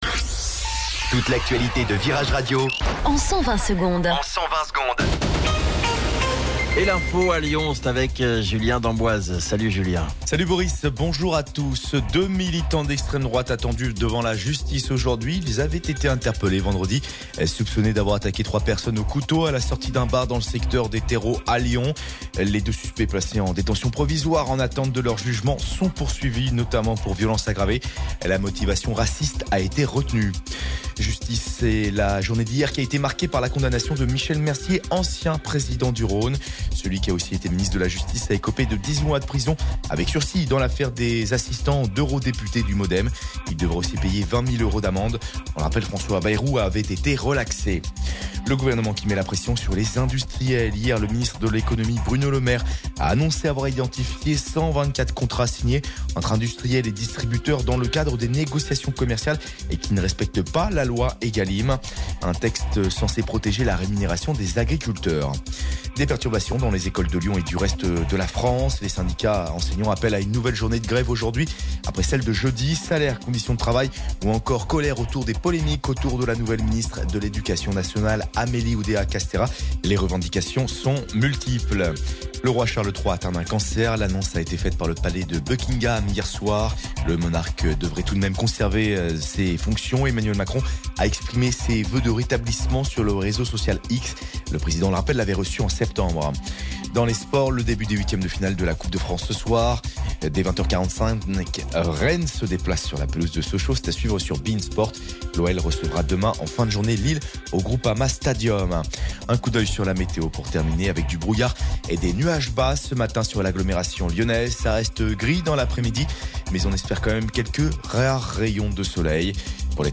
Flash Info Lyon 06 Février 2024 Du 06/02/2024 à 07h10 Flash Info Télécharger le podcast Partager : À découvrir Alerte Canicule : Le Eddie’s Dive Bar d’Iron Maiden débarque en France !